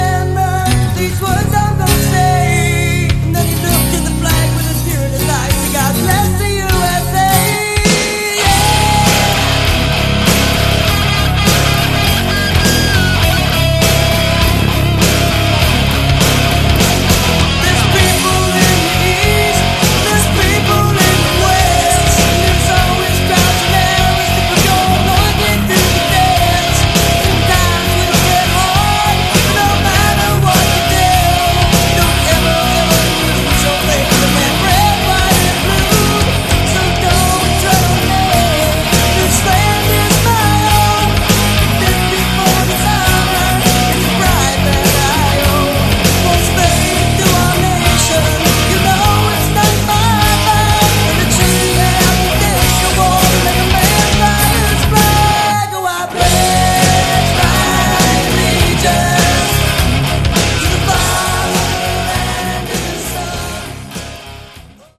Category: Hard Rock
lead and backing vocals
electric and acoustic guitars
bass
drums